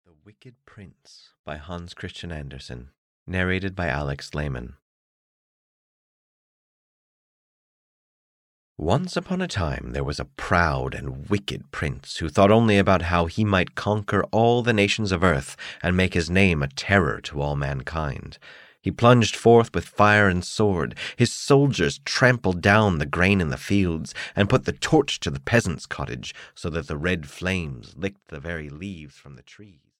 The Wicked Prince (EN) audiokniha
Ukázka z knihy